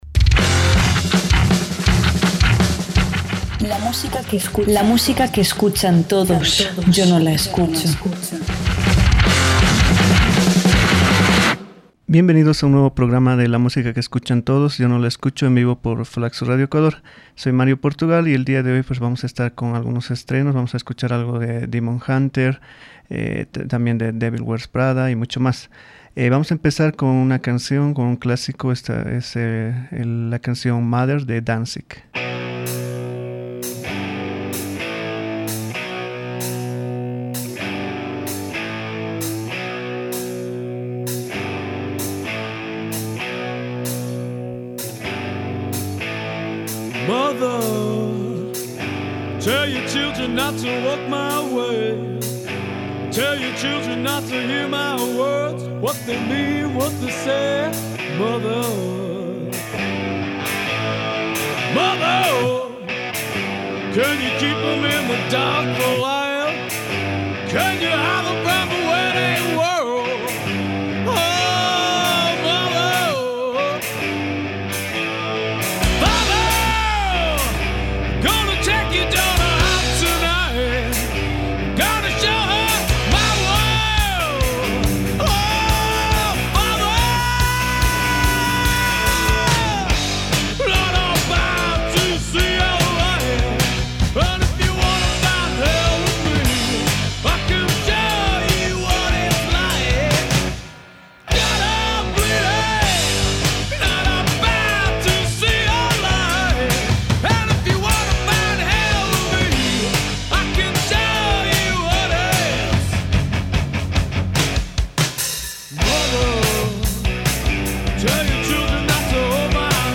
metalcore
un sonido rock más clásico
El heavy metal más clásico
grupo femenino